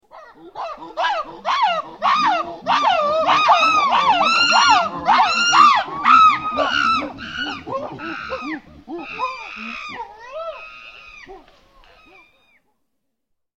Monkey ringtone free download
Animals sounds